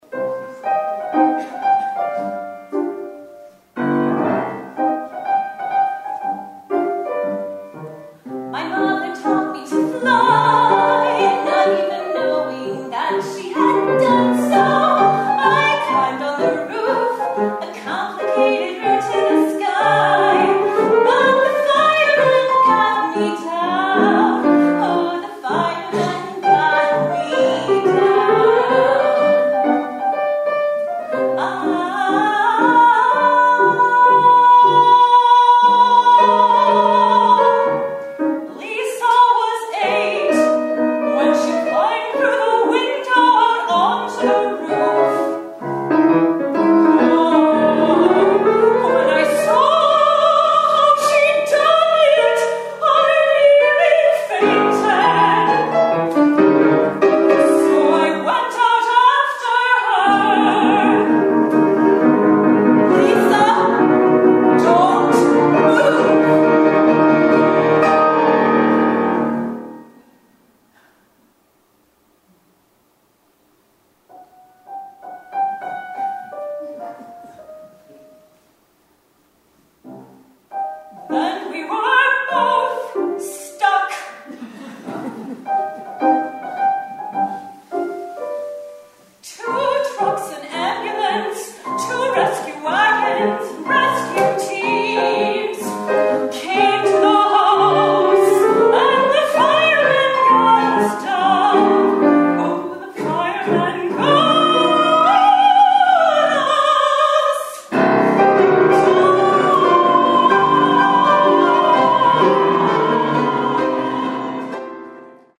Entertaining for the singer, pianist, and audience…triple threat!